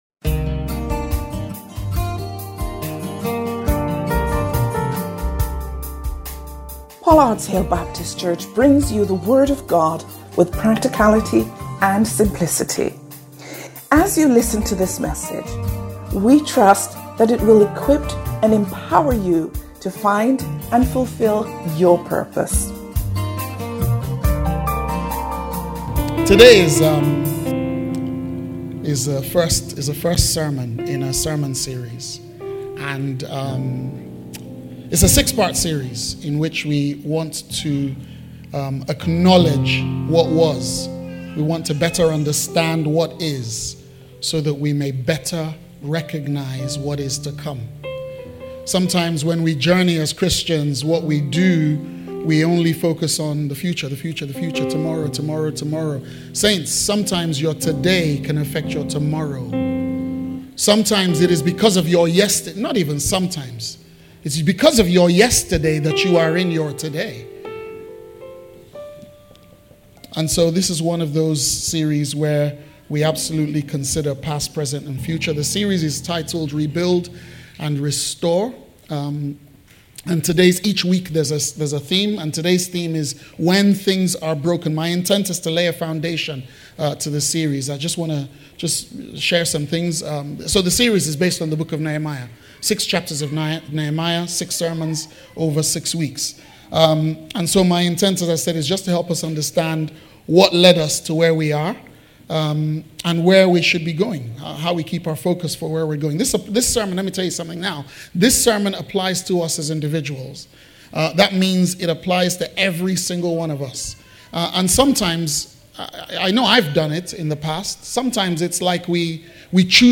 Watch this service and more on our YouTube channel – CLICK HERE